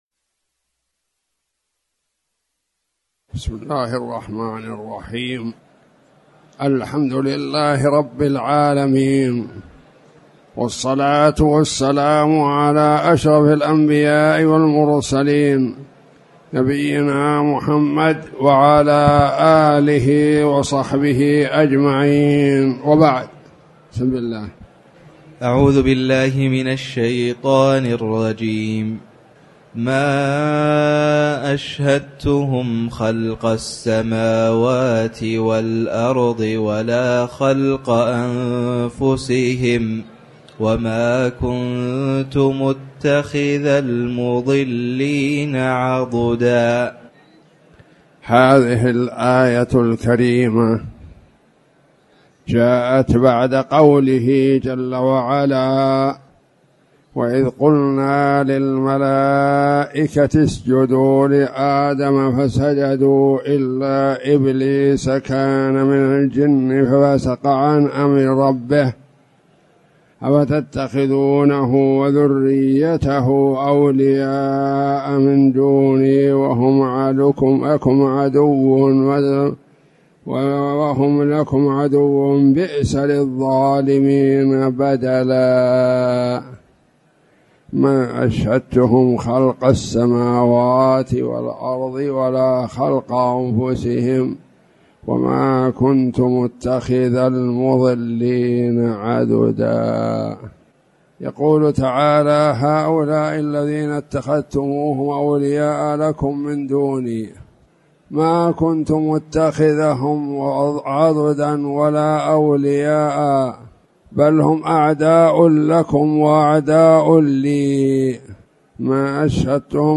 تاريخ النشر ١٧ ذو القعدة ١٤٣٨ هـ المكان: المسجد الحرام الشيخ